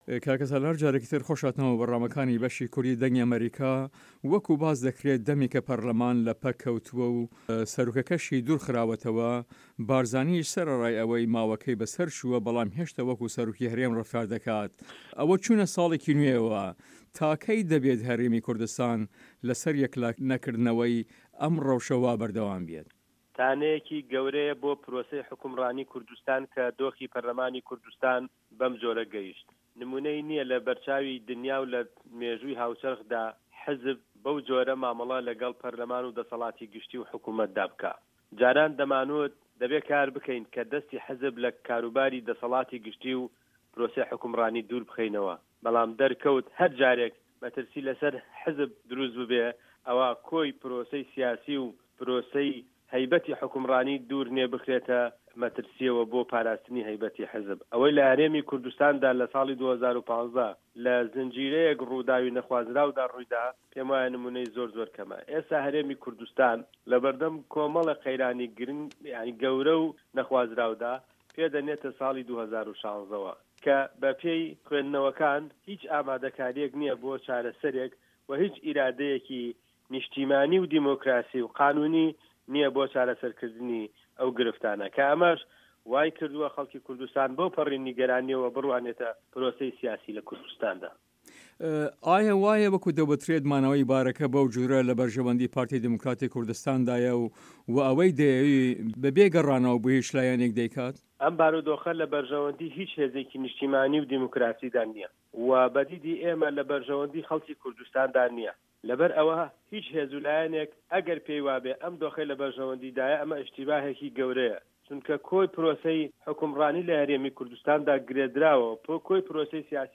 سالار مەحمود ئەندام پەرلەمانی هەرێمی کوردستان لە هەڤپەیڤینێکدا لەگەڵ بەشی کوردی دەنگی ئەمەریکا دەڵێت" تانەیەکی گەورەیە بۆ پرۆسەی فەرمانرەوایی کوردستان کە دۆخی پەرلەمانی کوردستان بەم جۆرە گەیشت، نموونەی نییە لەبەر چاوی دونیا و لە مێژووی هاوچەرخدا حیزب بەو جۆرە سەودا لەگەڵ پەرلەمان و دەسەڵاتی گشتی و حکومەت (میری) دا بکات.